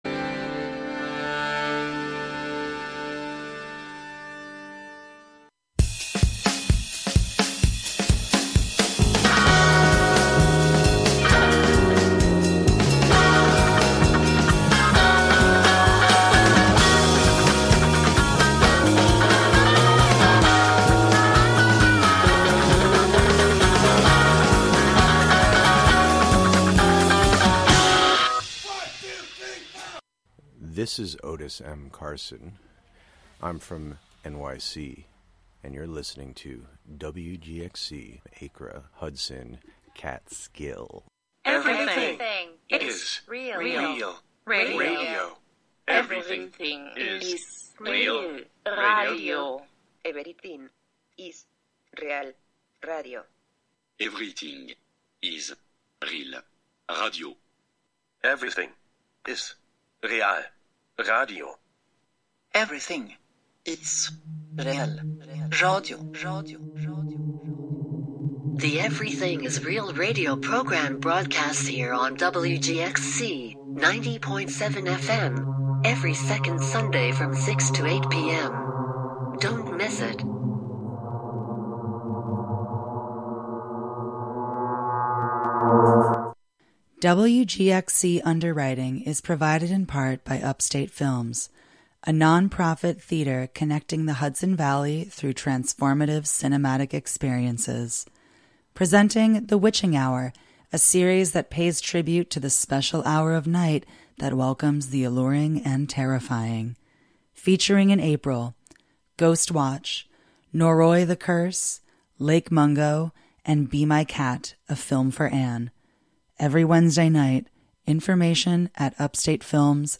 Singing Children: Apr 07, 2025: 10am - 11am